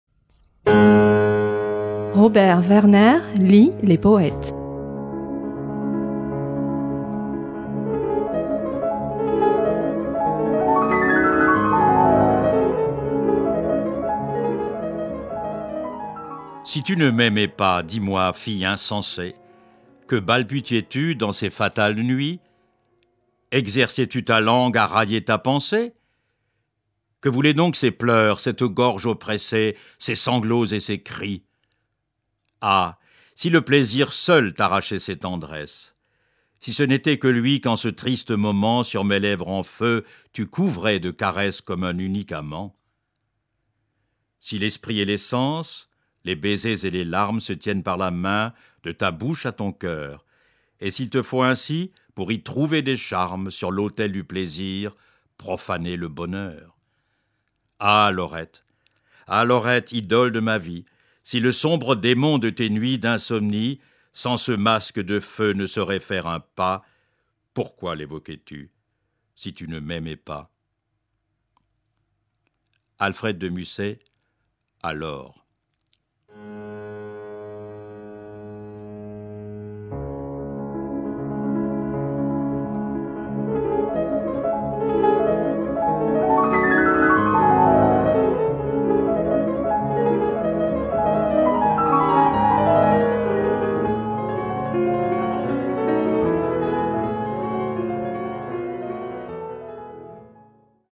lit cette semaine A Laure d’Alfred de Musset (1810-1857), poème dans lequel il affirme son souhait de voir réunis les élans du corps et ceux du cœur.